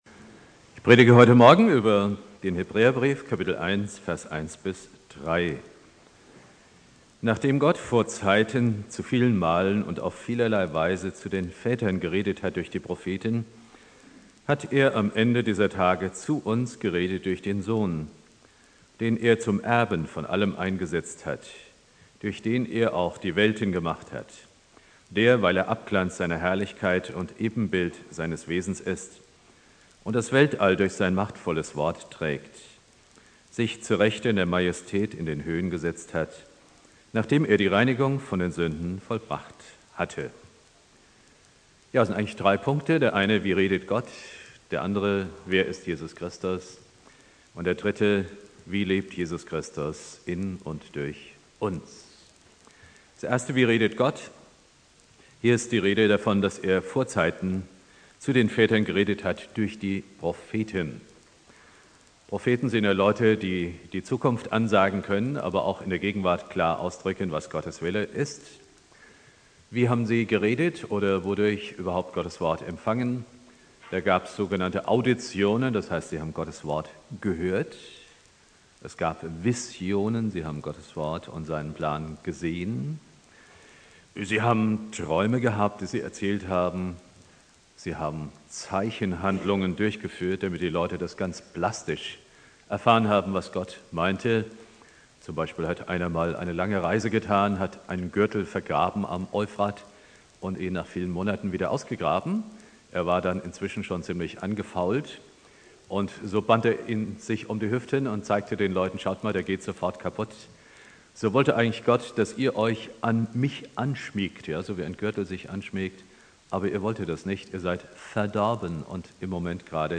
Predigt
2.Weihnachtstag Prediger